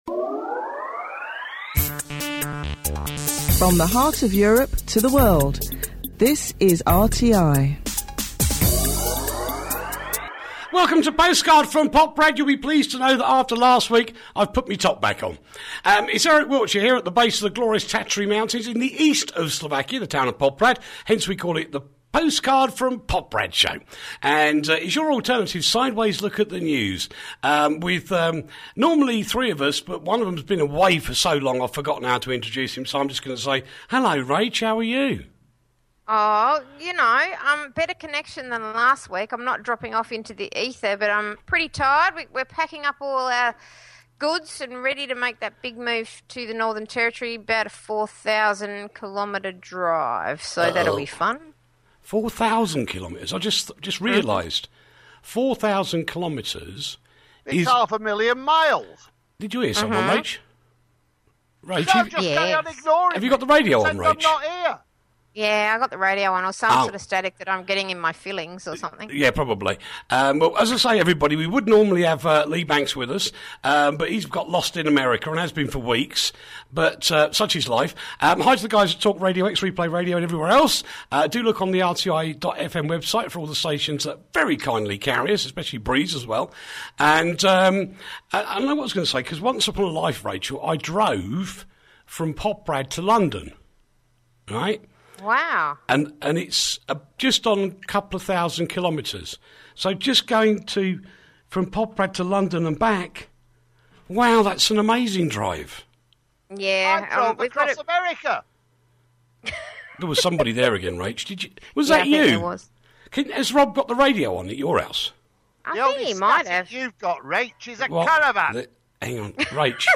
the alternative news show